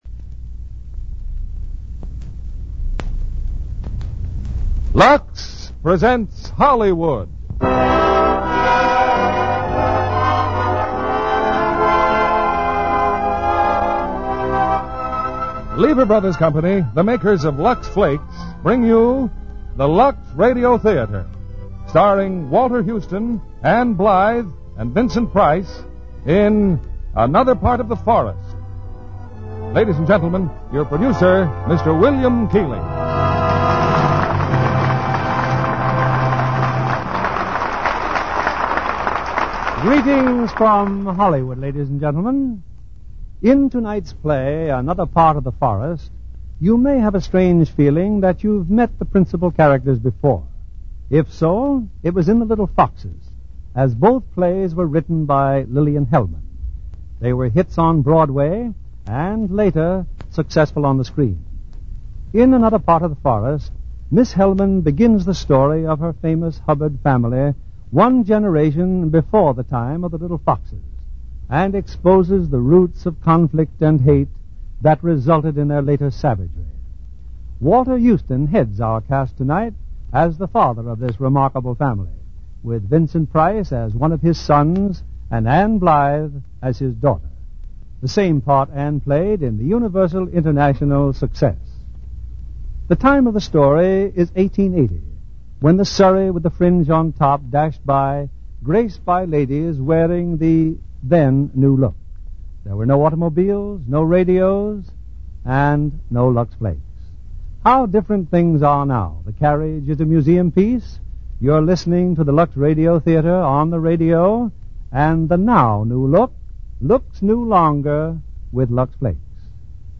Lux Radio Theater Radio Show
Another Part of the Forest, starring Vincent Price, Walter Huston, Ann Blythe